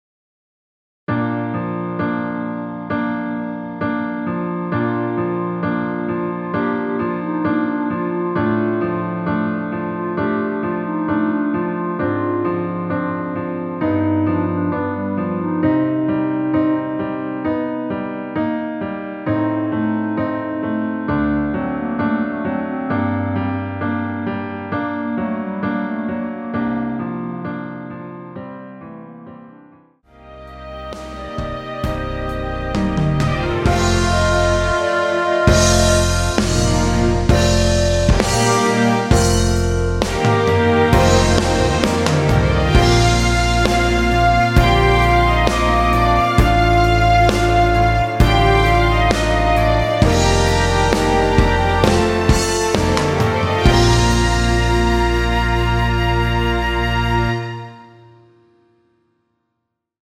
전주 없이 시작하는 곡이라 전주 1마디 만들어 놓았으며
원키에서(-2)내린 멜로디 포함된 MR입니다.(미리듣기 확인)
Bb
앞부분30초, 뒷부분30초씩 편집해서 올려 드리고 있습니다.